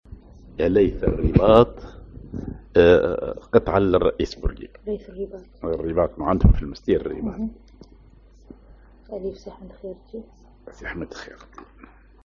Maqam ar راست على النوا
genre نشيد